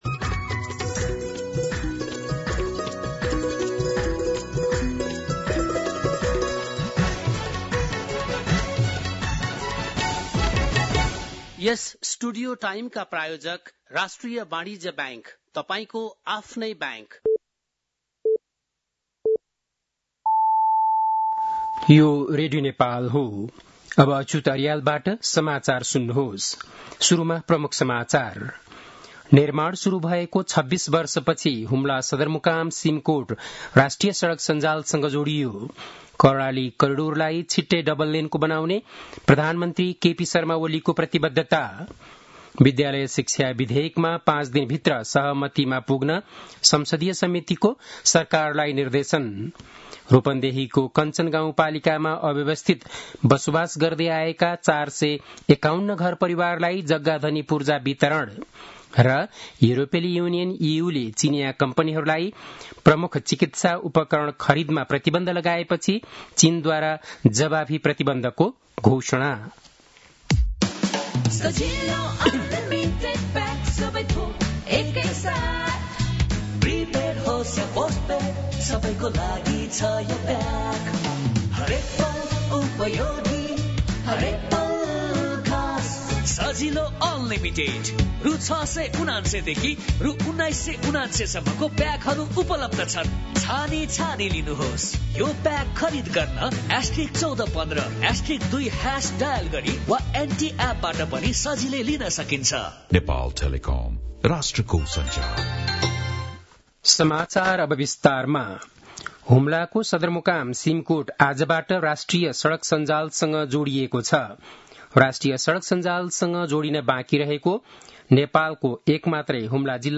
बेलुकी ७ बजेको नेपाली समाचार : २२ असार , २०८२
7.-pm-nepali-news-1-1.mp3